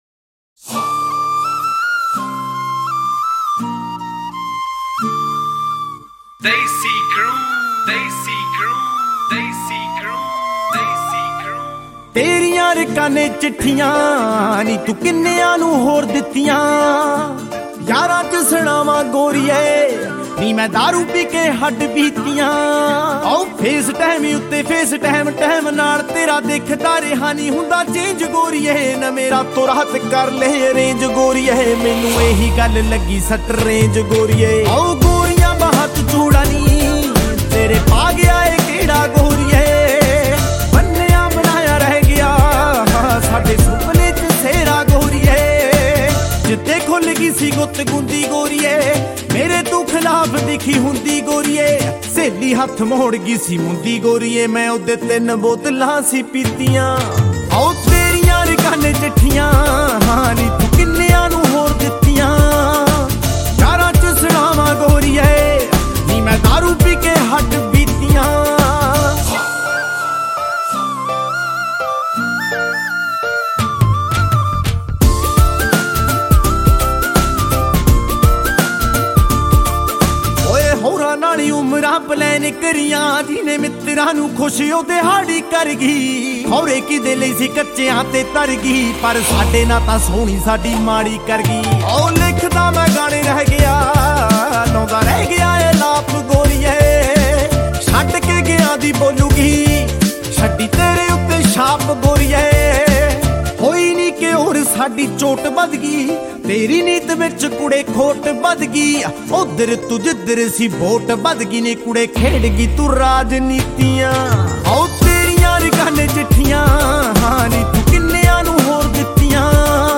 2020 Punjabi Mp3 Songs
Punjabi Bhangra MP3 Songs